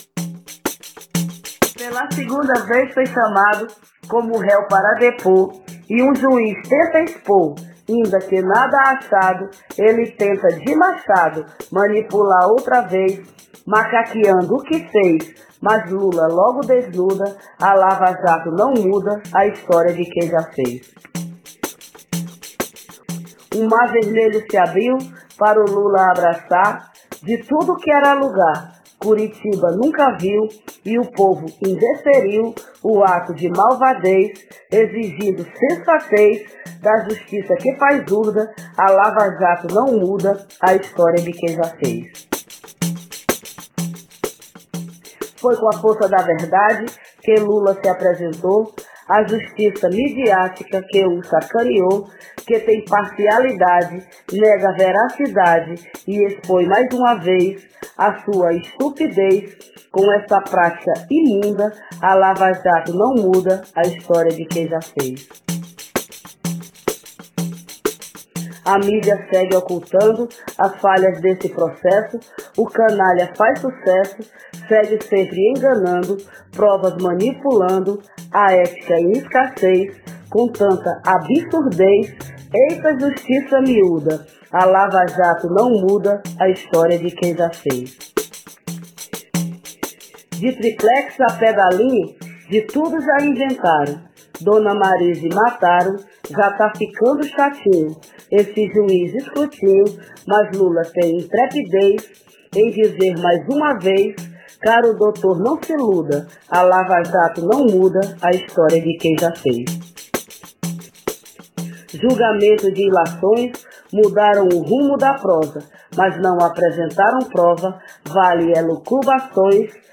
Cordel sobre depoimento de Lula a Lava jato foi ao ar no Programa Brasil de Fato - Edição Pernambuco